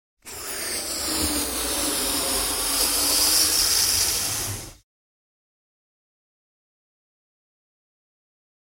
Звук, де фуга надувається з отруйними іскрами